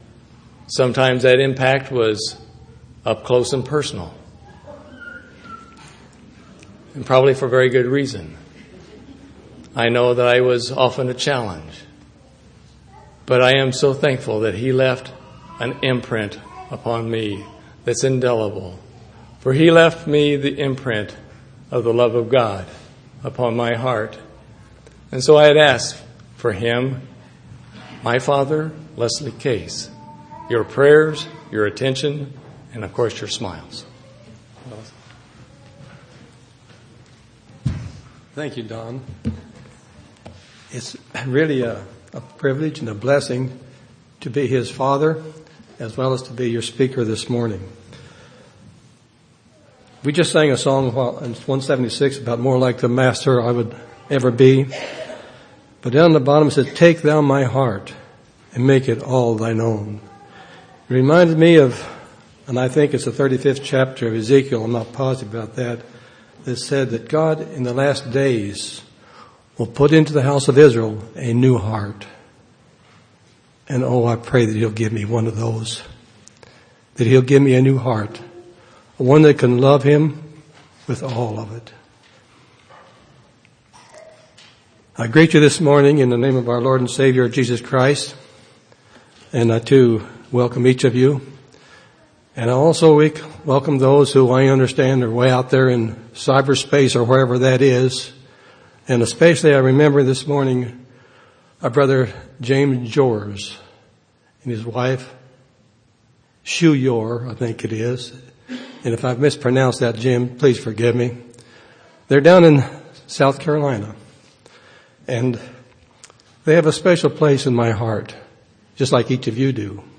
9/13/2009 Location: Temple Lot Local Event